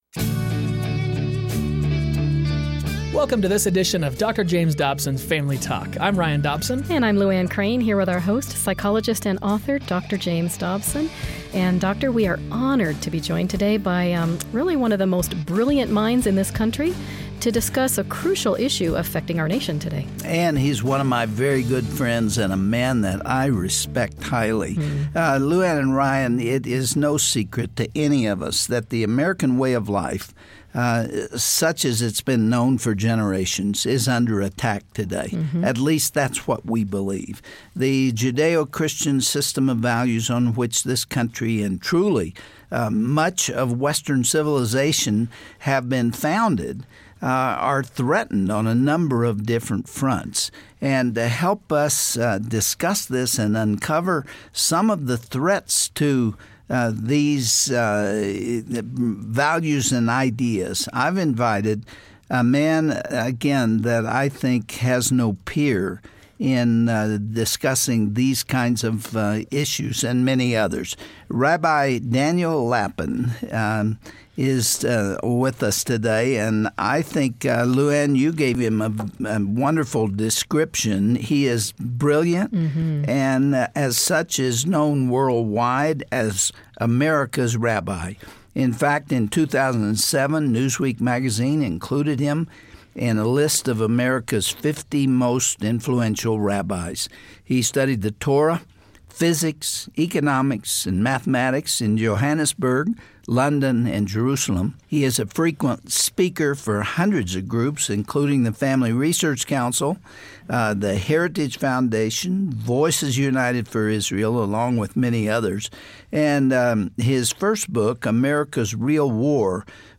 Dr. Dobson is joined by Rabbi Daniel Lapin where they discuss when our culture's moral and spiritual free-fall began. Rabbi Lapin will explain how God's people can have a role in righting America's direction. It's a conversation you won't want to miss.